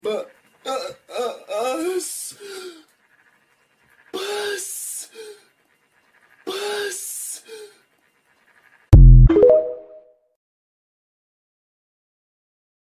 Bus Sound Effect Free Download
Bus